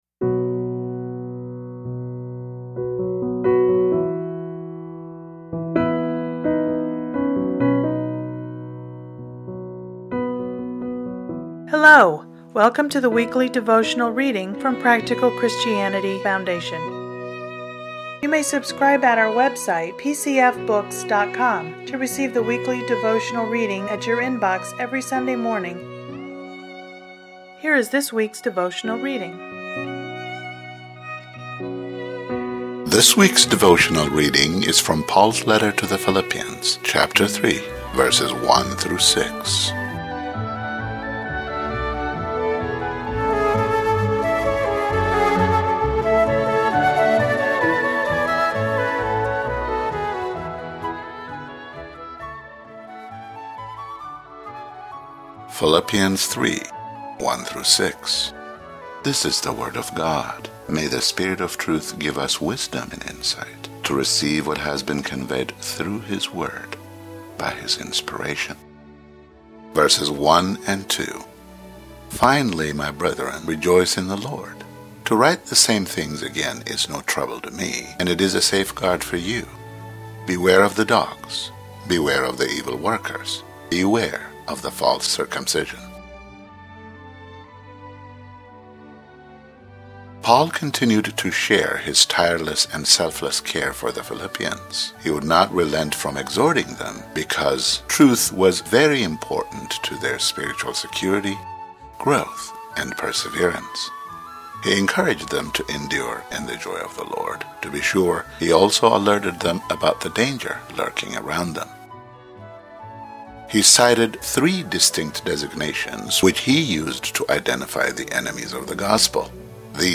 Listen to today's devotional commentary